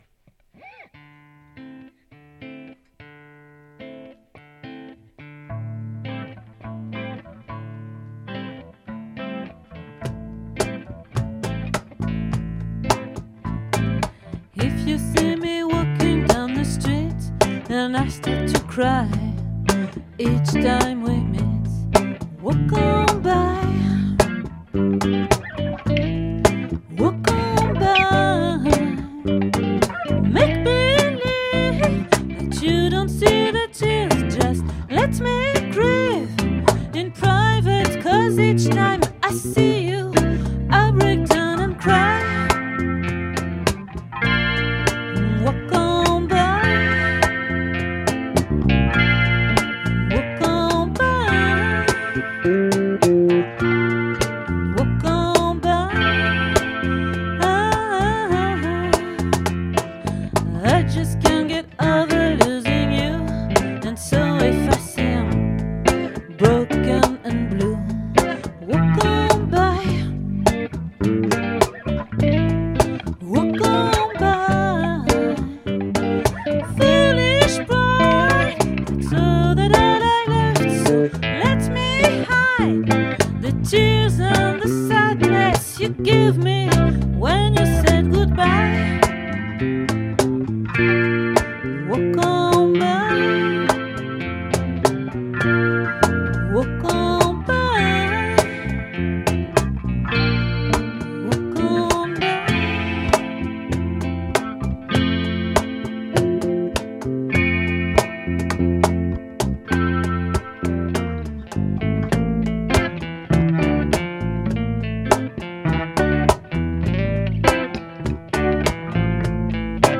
🏠 Accueil Repetitions Records_2022_06_08